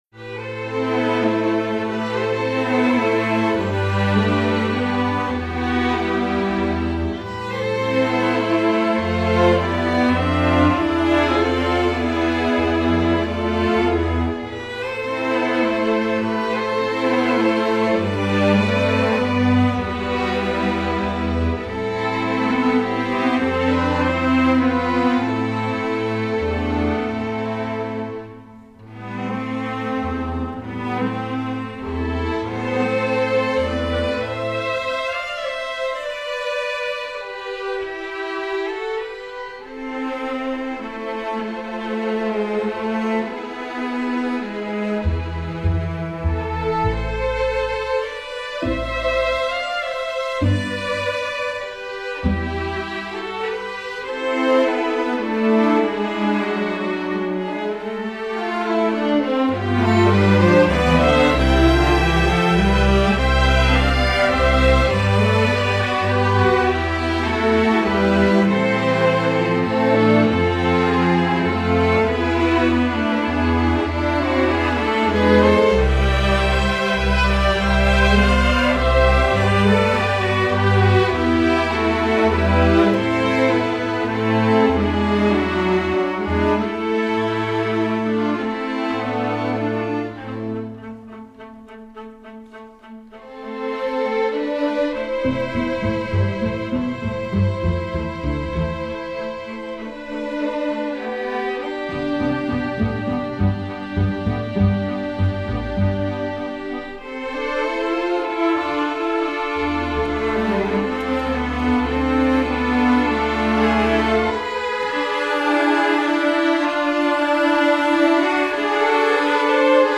Composer: Folk Song
Voicing: String Orchestra